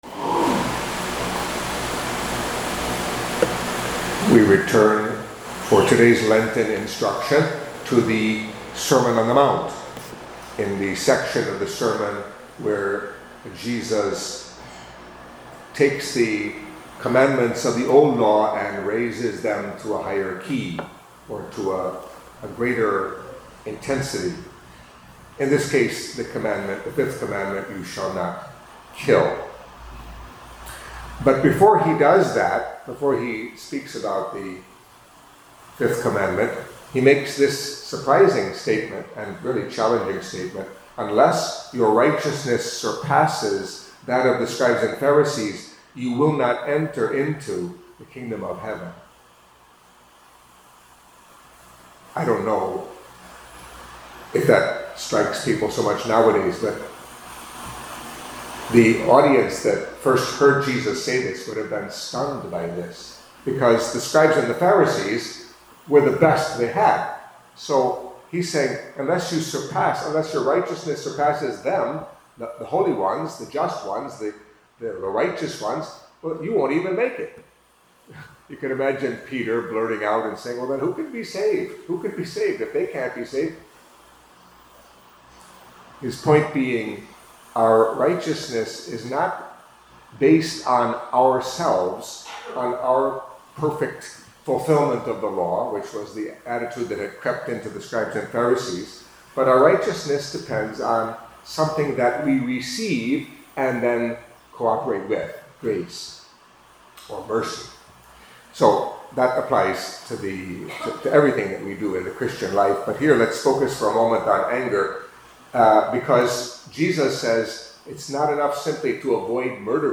Catholic Mass homily for Friday of the First Week of Lent